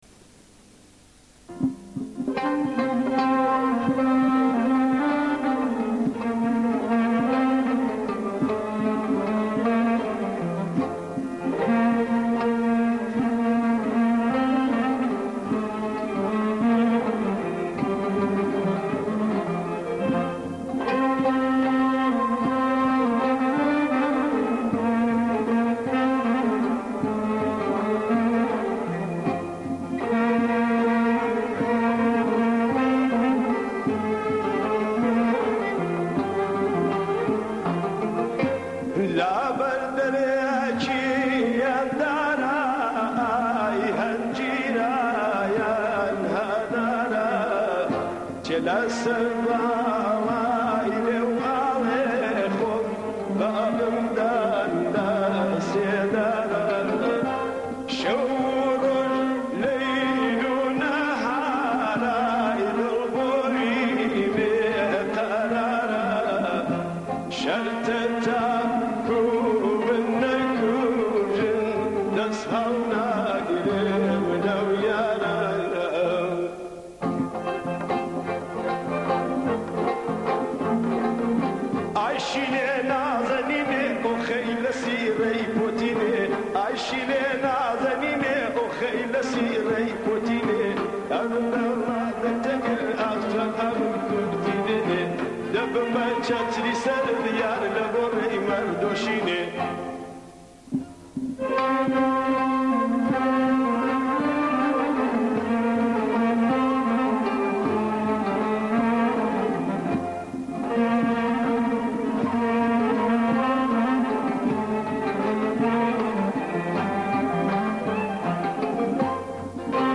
آهنگ کردی